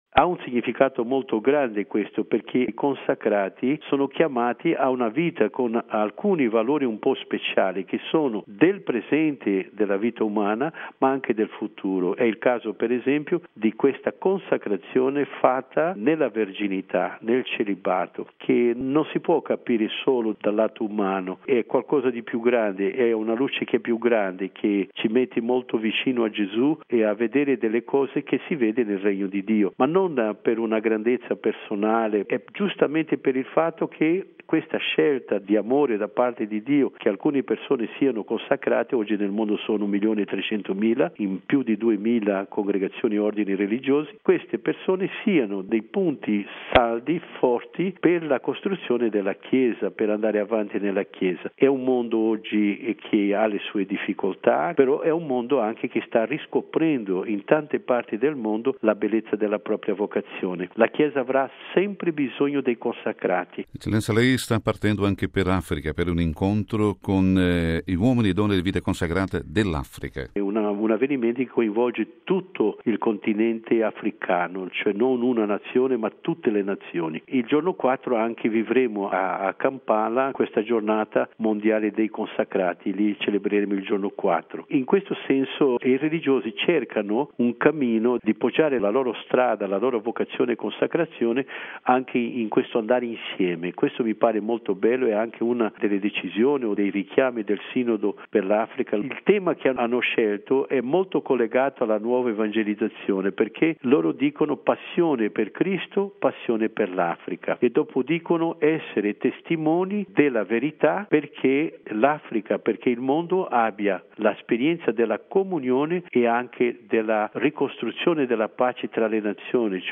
• Simposio a Roma sugli abusi compiuti su minori da esponenti del clero: intervista con mons.